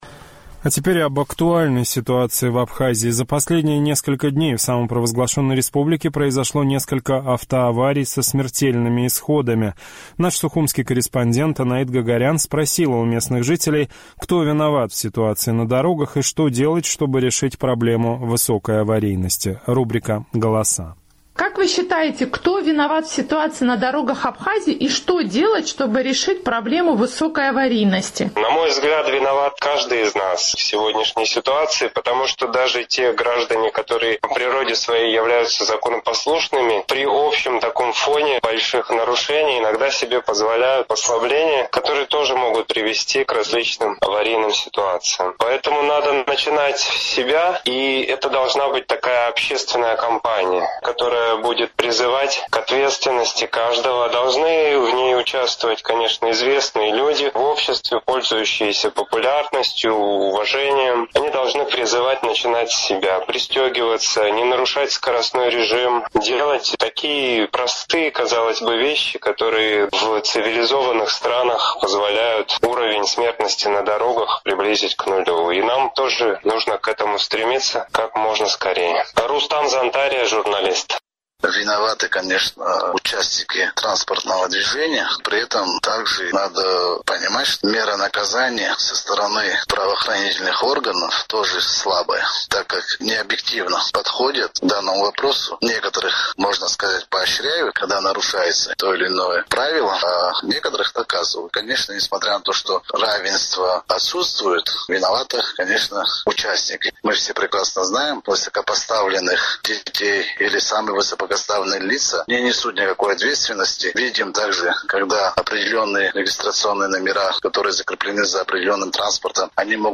Сухумский опрос – о ситуации на дорогах Абхазии